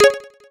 CD-ROOM/Assets/Audio/SFX/recogerdisco2.wav at main
recogerdisco2.wav